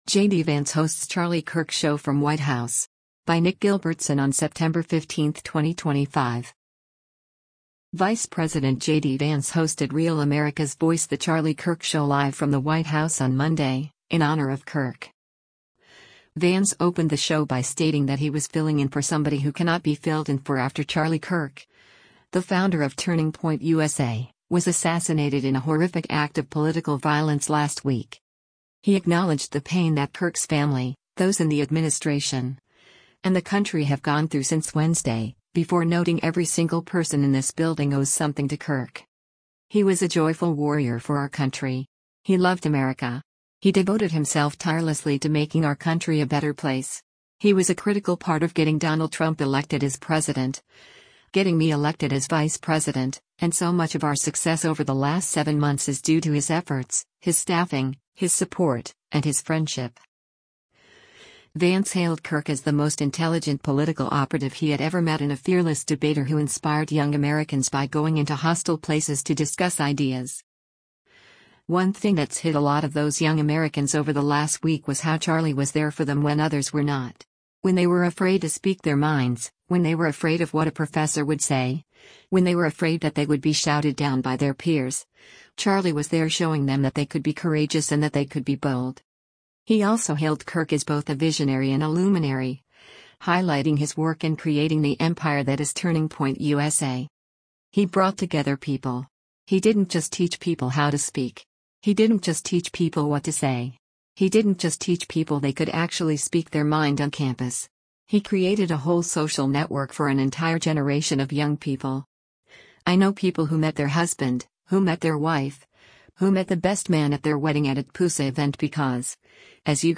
Vice President JD Vance hosted Real America’s Voice The Charlie Kirk Show live from the White House on Monday, in honor of Kirk.